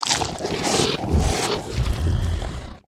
255081e1ee Divergent / mods / Soundscape Overhaul / gamedata / sounds / monsters / bloodsucker / eat_0.ogg 49 KiB (Stored with Git LFS) Raw History
eat_0.ogg